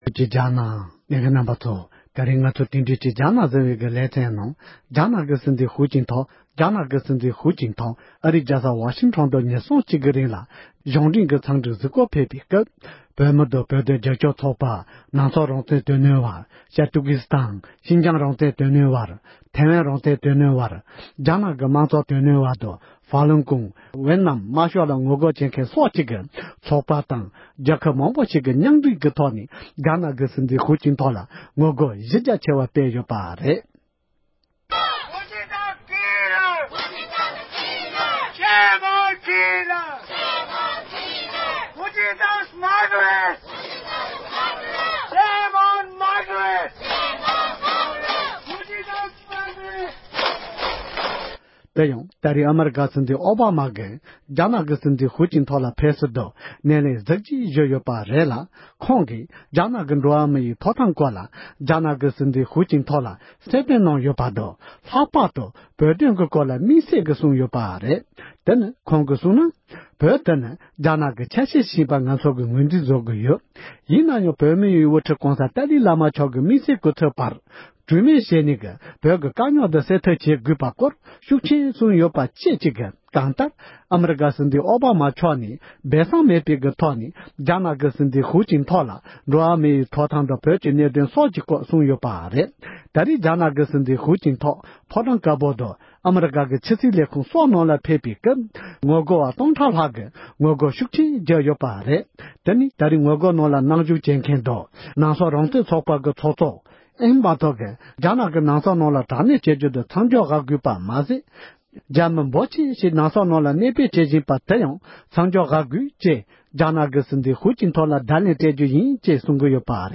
འབྲེལ་ཡོད་མི་སྣར་བཅའ་འདྲི་ཞུས་པ་ཞིག་ལ་གསན་རོགས་གནོངས༎